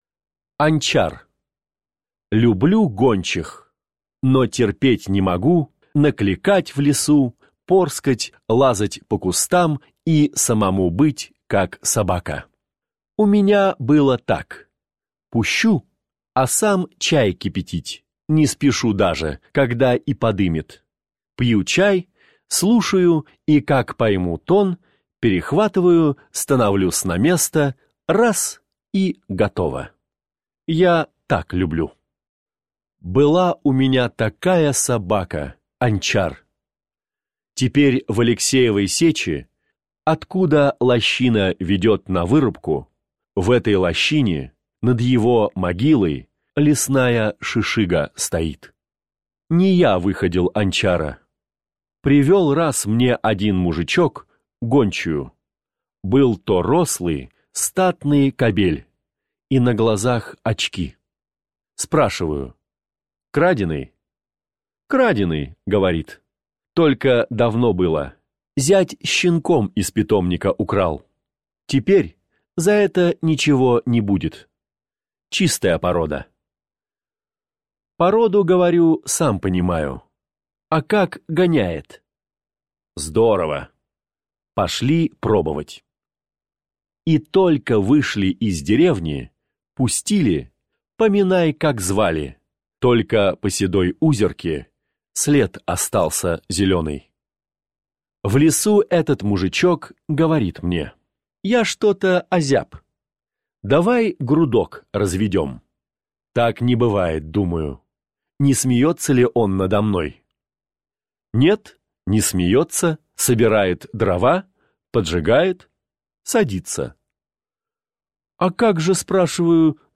Анчар - аудио рассказ Пришвина - слушать онлайн